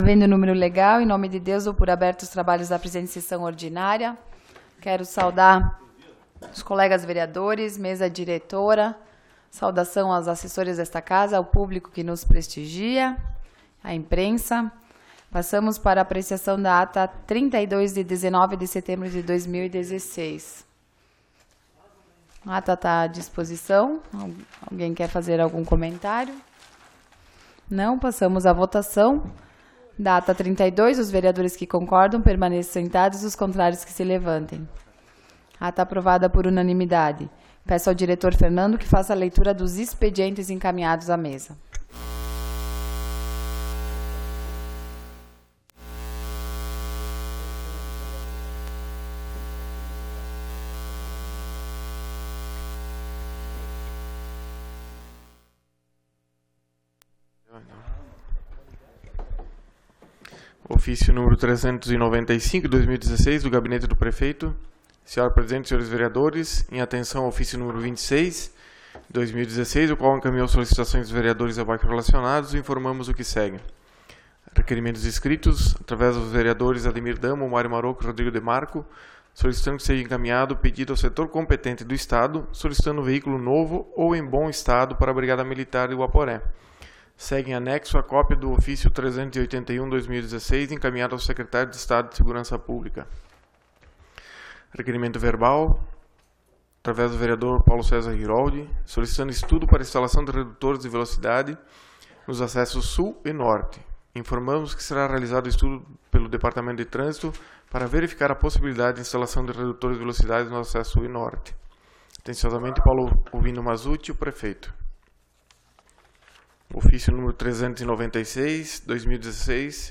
Sessão Ordinária do dia 26 de Setembro de 2016